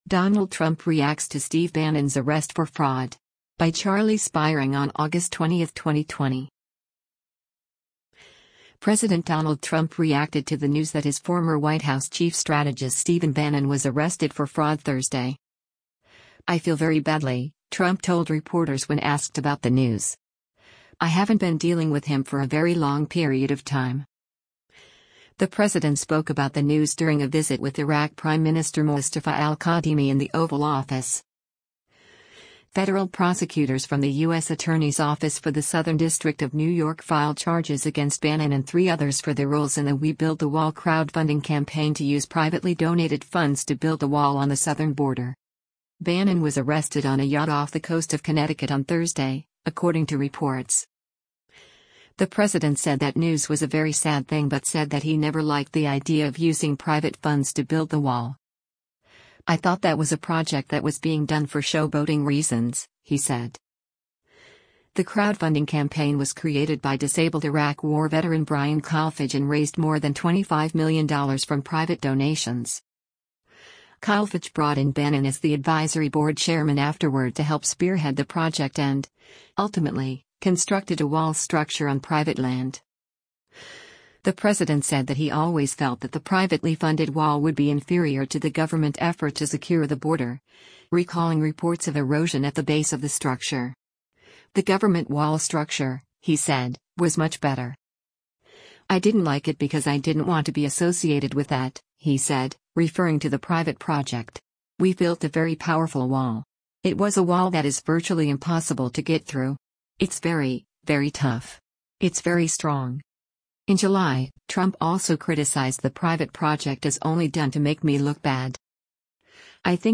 The president spoke about the news during a visit with Iraq Prime Minister Mustafa Al-Kadhimi in the Oval Office.